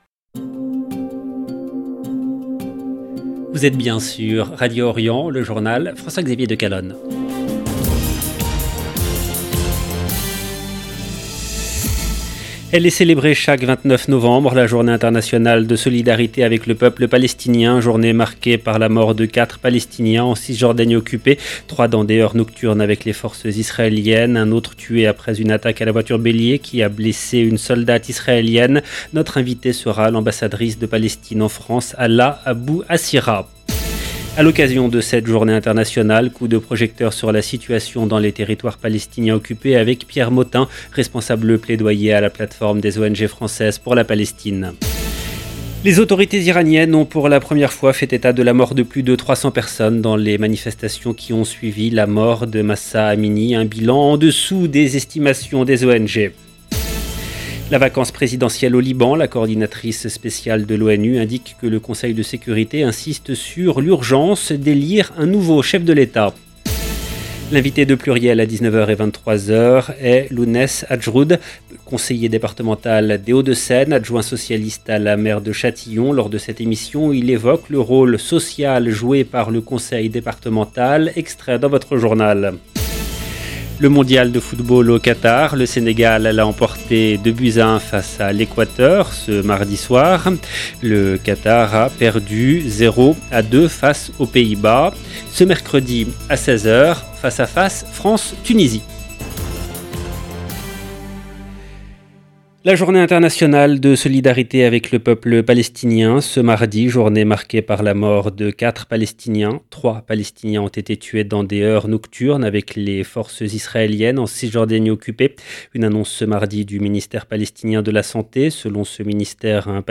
EDITION DU JOURNAL DU SOIR EN LANGUE FRANCAISE DU 29/11/2022